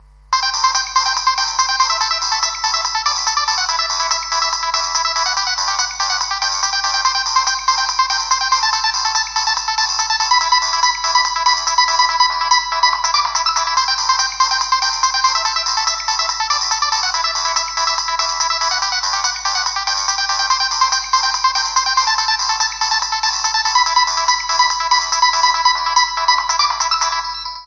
Nokia 1208 Ringtone - Botão de Efeito Sonoro